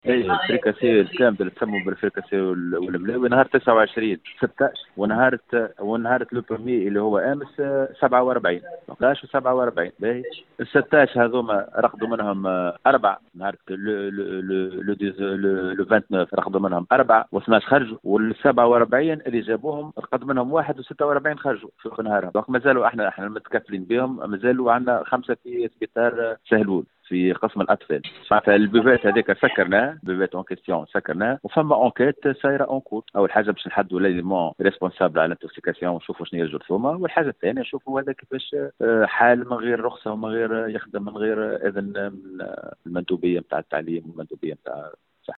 أفاد المدير الجهوي للصحة بسوسة محمد الغضباني في تصريح ل “ام اف ام”، أنه تم أمس نقل 47 تلميذ إلى قسم الأطفال بالمستشفى الجامعي سهلول إضافة إلى 16 تلميذ نُقلوا يوم 29 سبتمبر 2021، وذلك اثر تناولهم “فريكاسي” و ” ملاوي” قاموا بشرائها من مشربة إعدادية الهادي العامري بالقلعة الصغرى.